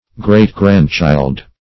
Search Result for " great-grandchild" : The Collaborative International Dictionary of English v.0.48: Great-grandchild \Great"-grand"child`\, n. The child of one's grandson or granddaughter.